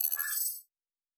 Additional Weapon Sounds 2_1.wav